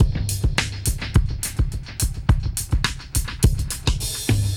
Index of /musicradar/dub-drums-samples/105bpm
Db_DrumsA_KitEcho_105_03.wav